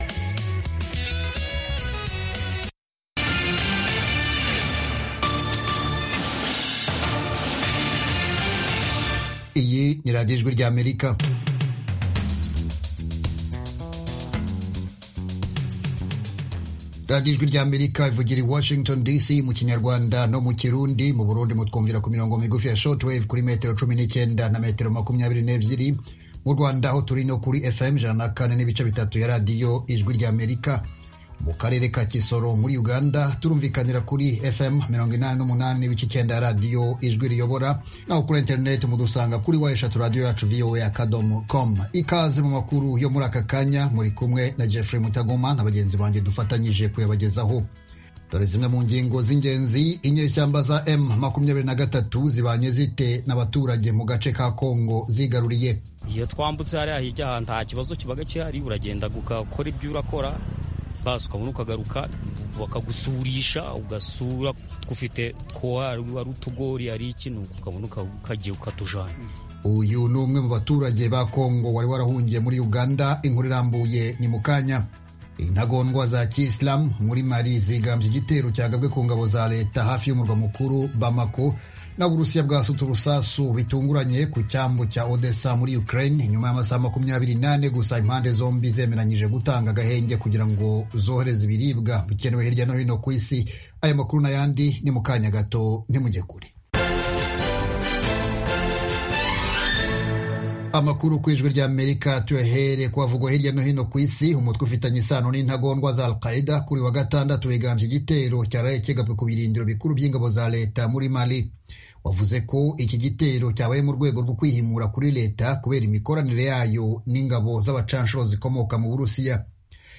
Amakuru y'Akarere (1600-1630 UTC): Amakuru atambuka i saa kumi n'ebyeri ku mugoroba mu Rwanda no mu Burundi. Akenshi, aya makuru yibanda ku karere k'ibiyaga bigari n'Afurika y'uburasirazuba.